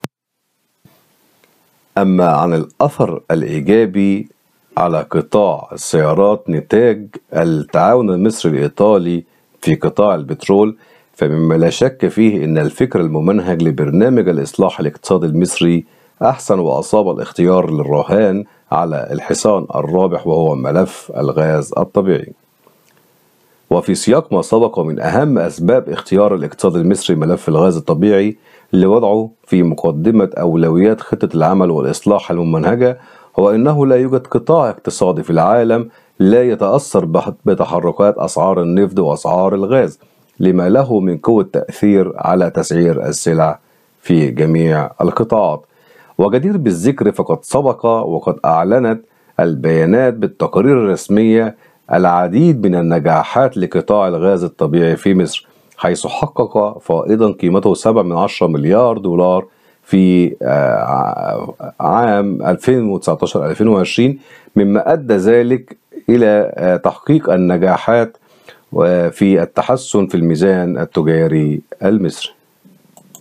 محلل اقتصادي